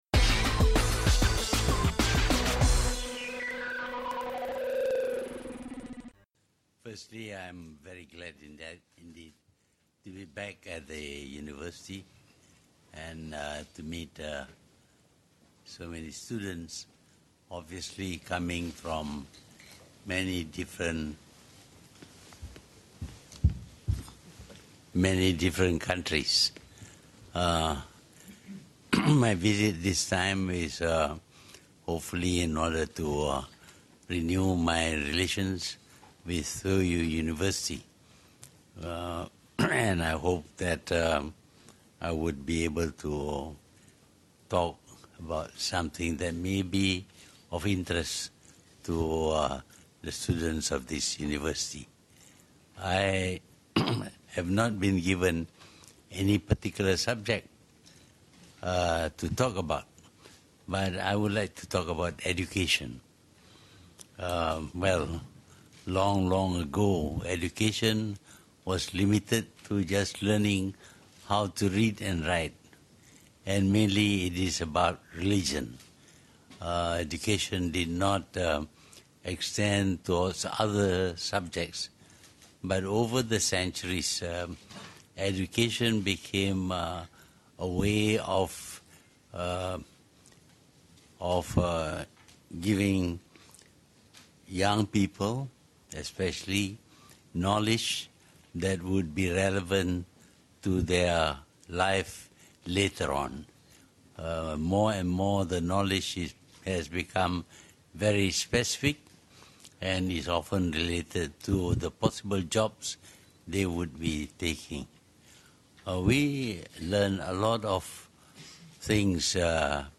Ucaptama Tun Dr Mahathir Mohamad di Universiti Toyo, Tokyo
Ikuti rakaman penuh Ucaptama Tun Dr Mahathir Mohamad di Universiti Toyo, Tokyo. Beliau berada di Jepun untuk rangka lawatan kerja selama tiga hari di sana.